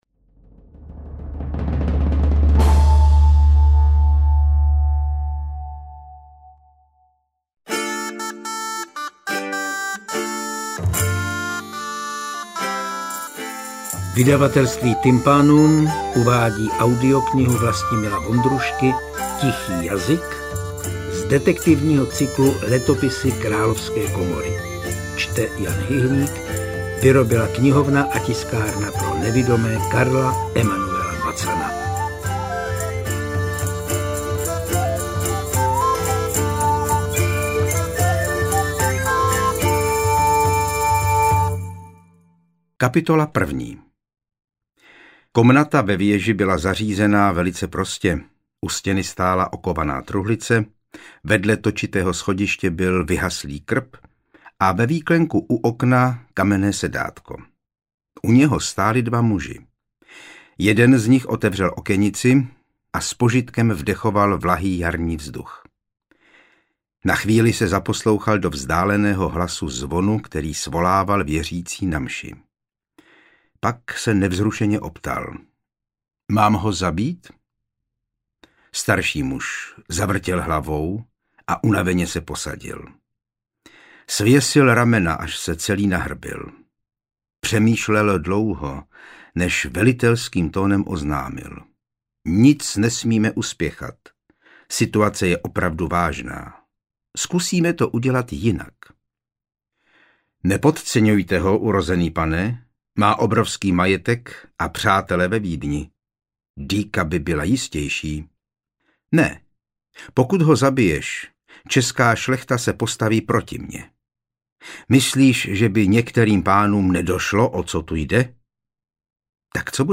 Interpret:  Jan Hyhlík